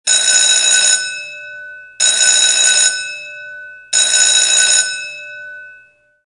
Оповещение СМС. Звонок телефона (ретро). mp3 звук
Категория: Живые звуки, имитация